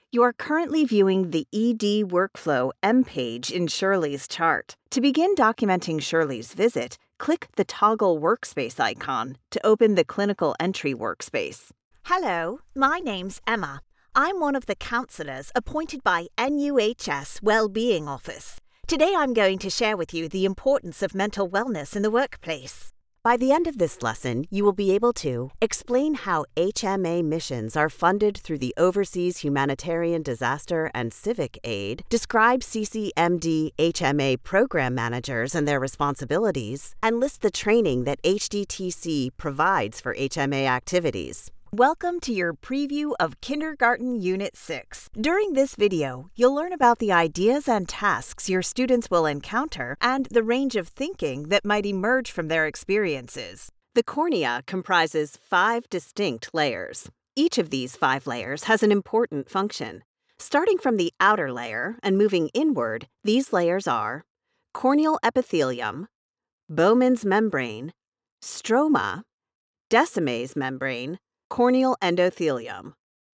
Narration
E-Learning